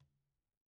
Tumba-HitN_v1_rr1_Sum.wav